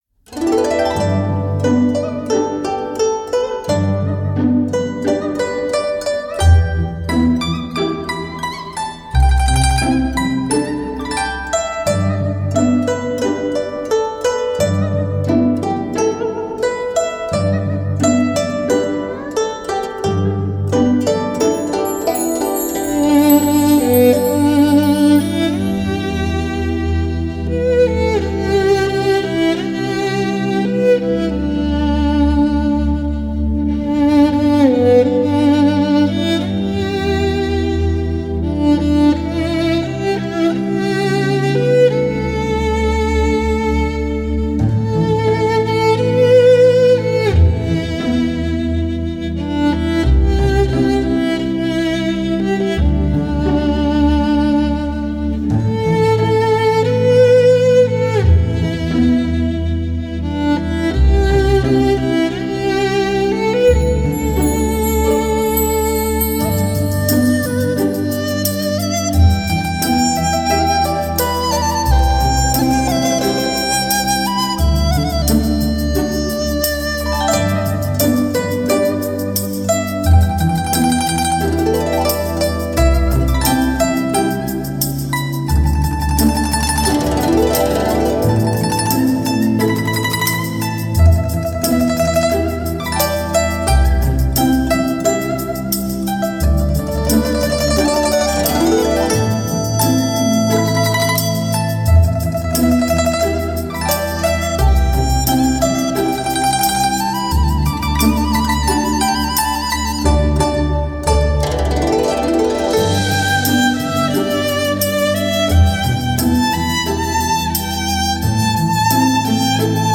浪漫而抒情的音乐，温馨、柔情的旋律总会让一颗浮躁的心，瞬间安静下来